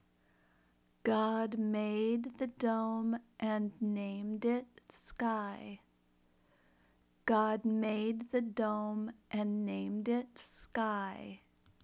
Speaking Exercise
If you are learning American English, imitate her pronunciation the best you can.